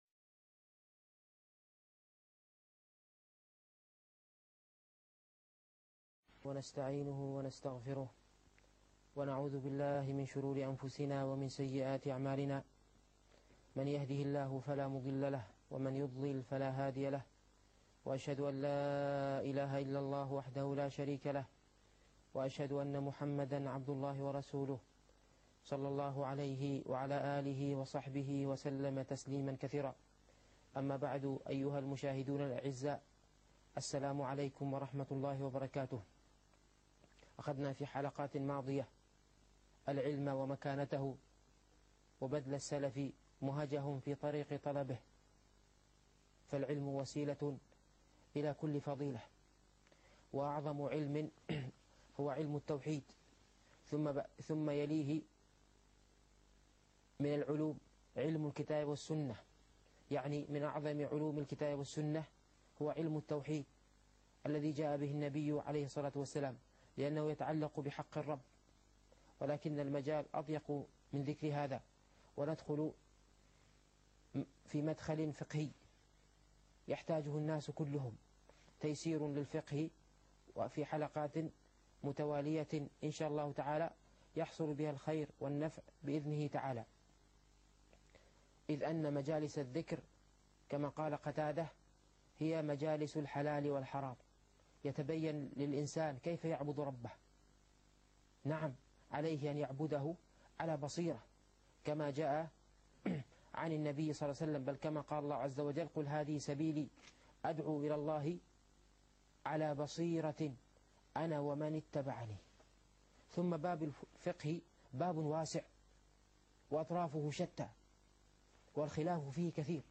الفقه الميسر - الدرس الثاني